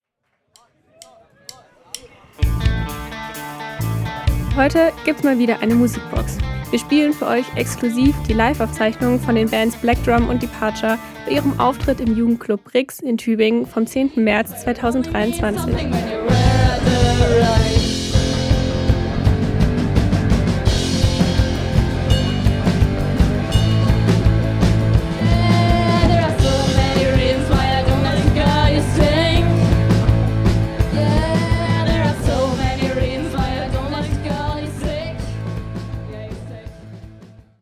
Die Bude war voll, die Stimmung am überkochen.